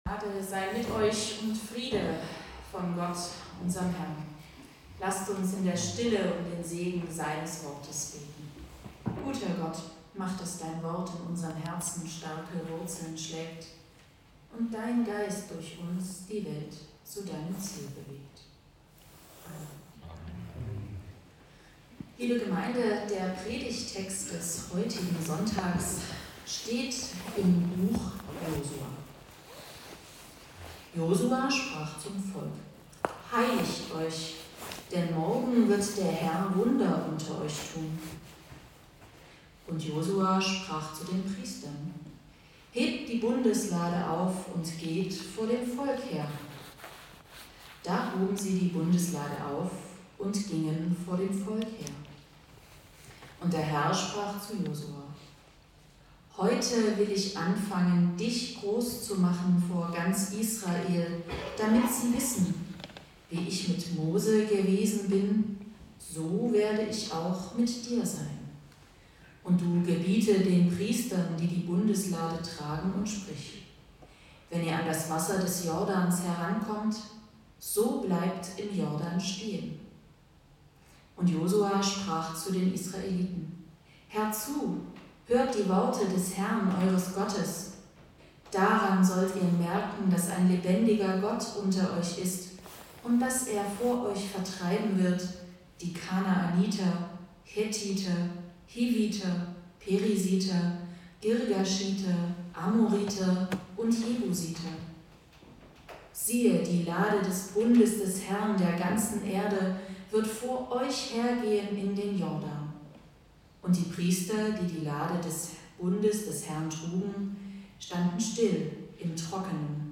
Josua 3;5-11 u. 17 Gottesdienstart: Predigtgottesdienst Wildenau « 2024